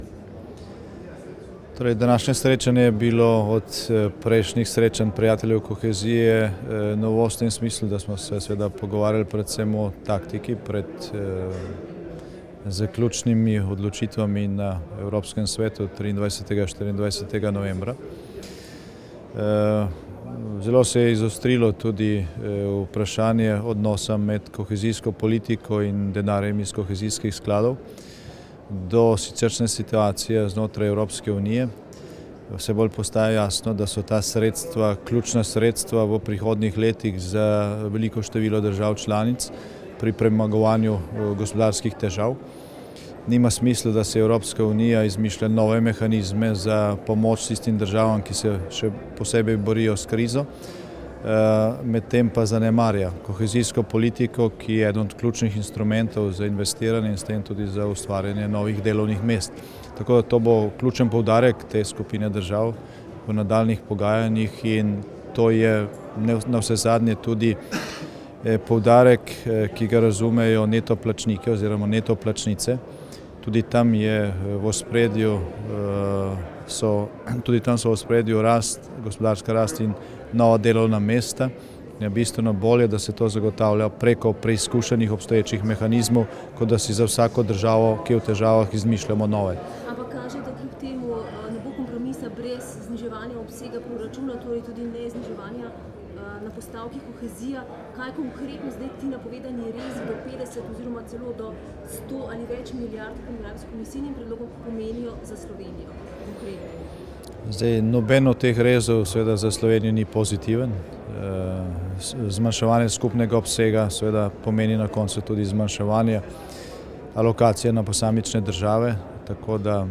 Predsednik Vlade RS Janez Janša se je danes udeležil tretjega srečanja Prijateljev kohezije, ki je tokrat potekalo v Bruslju. V izjavi za medije je povedal, da je današnje srečanje bilo v primerjavi s prejšnjimi novost, saj so se pogovarjali predvsem o taktiki delovanja pred zaključnimi odločitvami na Evropskem svetu.